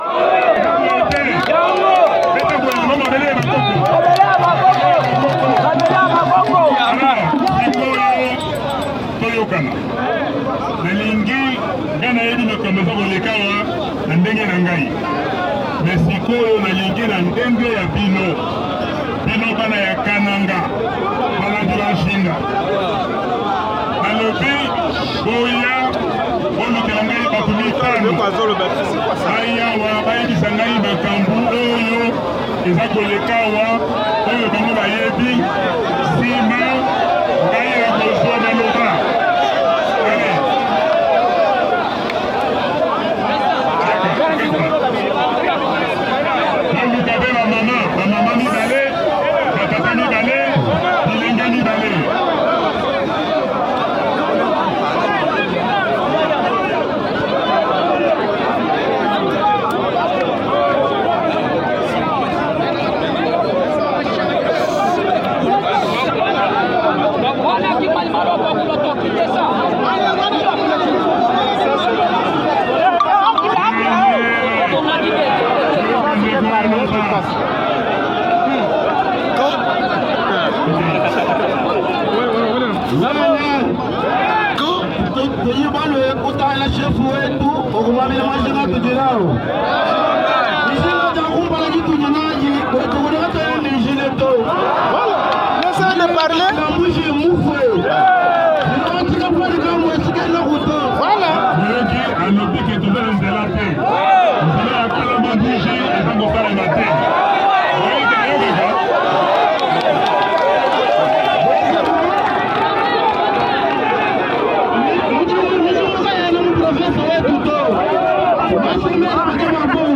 Meeting-Martin-Fayulu-a-Kananga-.mp3